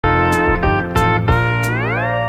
알림음 8_TopOfTheWorld.mp3